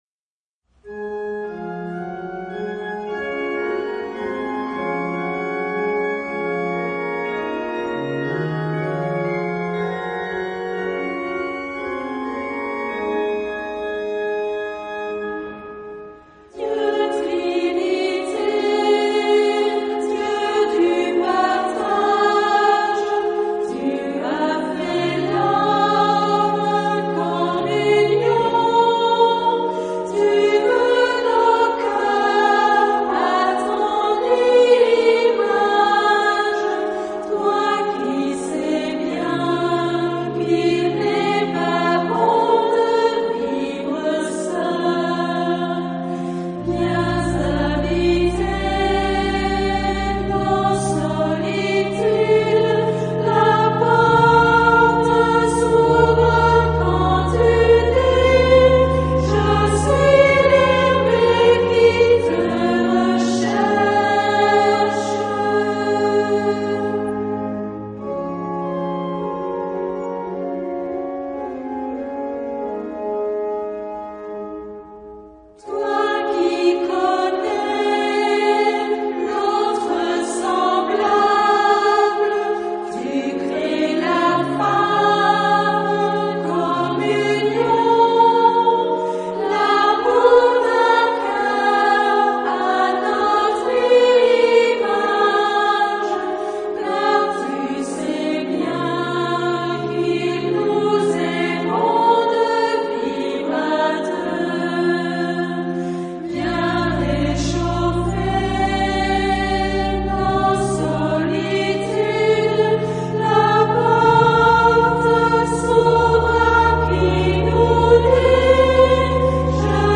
Genre-Style-Forme : Hymne (sacré)
Caractère de la pièce : cantabile
Type de choeur : SA  (2 voix égales )
Instruments : Orgue (1)
Tonalité : ré majeur